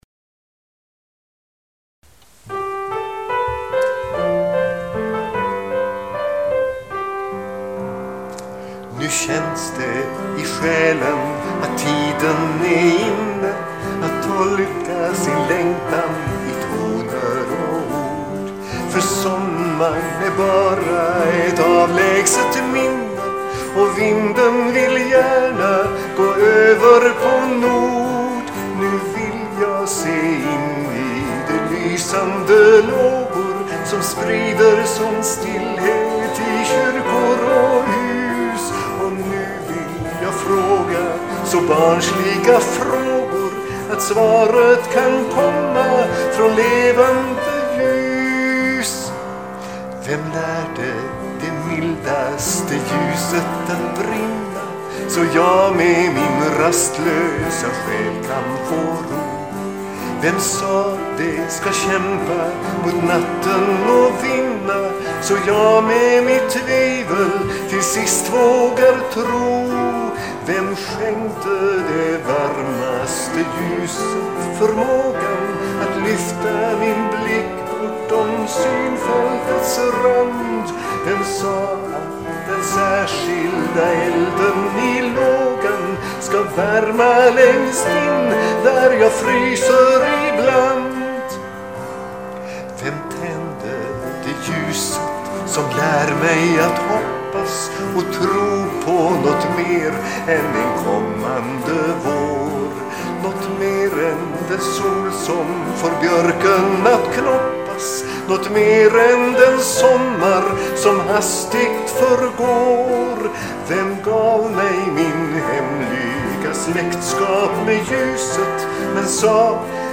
Det rör sig om inspelningar med sång till eget pianoackompanjemang.
Jag ber den som lyssnar notera att alla visorna är tagna ”live”.